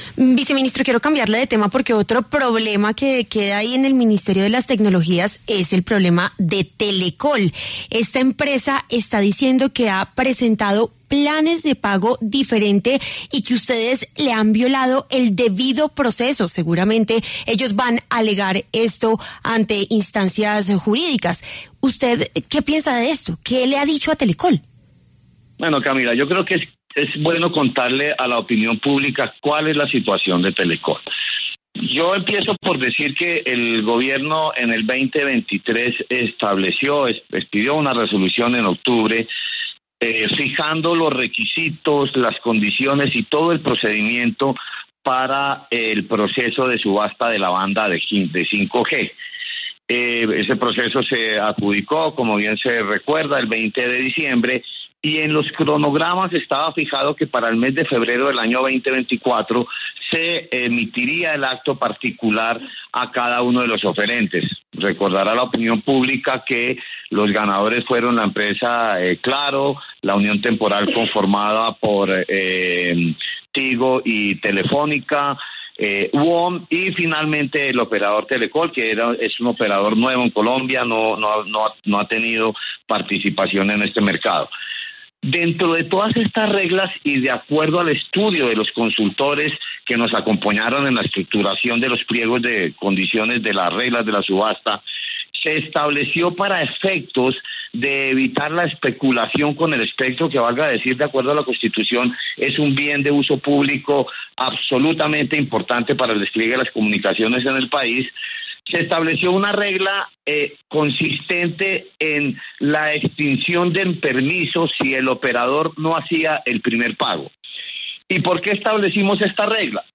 En entrevista con W Radio, el viceministro de Conectividad, Gabriel Jurado, explicó que las condiciones del proceso de la subasta 5G fueron establecidas desde octubre de 2023.